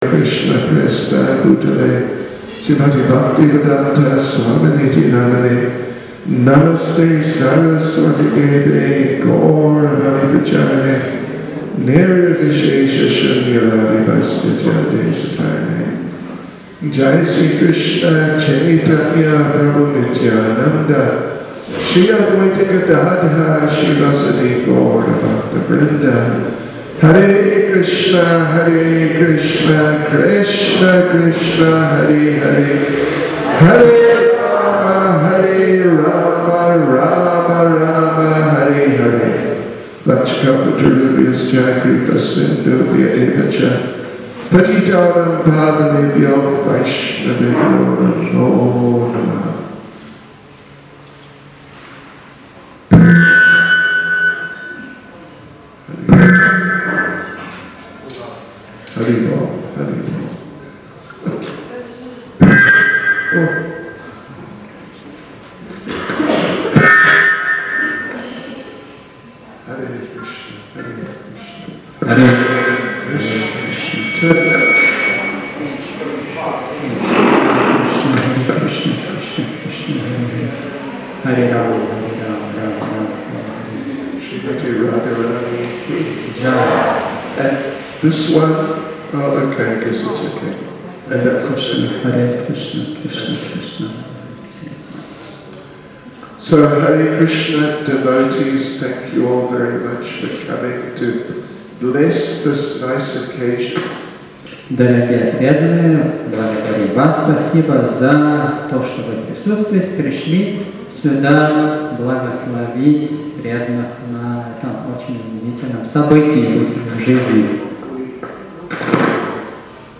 Gaura Mandala Parikrama 42(Initiation Lecture)